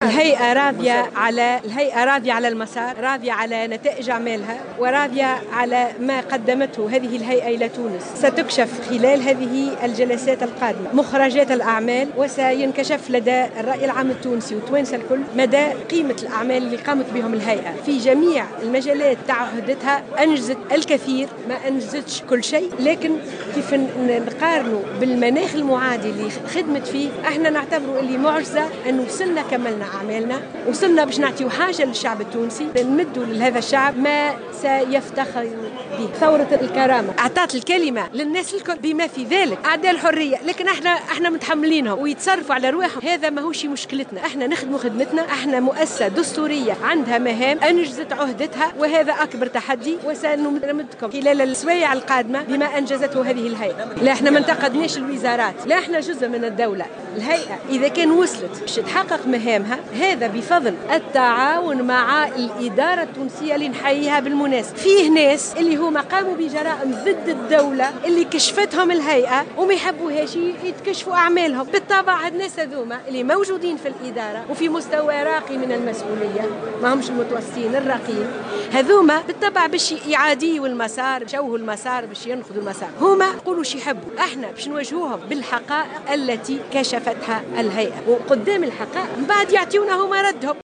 قالت سهام بن سدرين رئيسة هيئة الحقيقة والكرامة في تصريح لمراسلة الجوهرة "اف ام" اليوم الجمعة أن الهيئة راضية على مسارها و على نتائج أعمالها وعلى ما قدمته لتونس مؤكدة أنها ستكشف خلال هذه الجلسات القادمة مخرجات أعمالها.